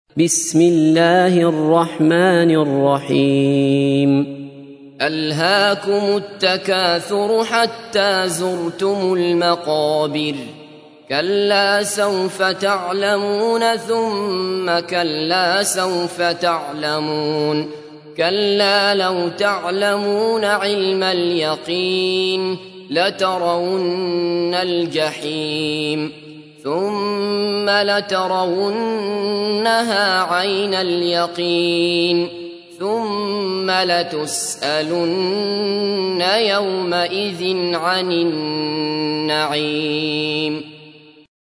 تحميل : 102. سورة التكاثر / القارئ عبد الله بصفر / القرآن الكريم / موقع يا حسين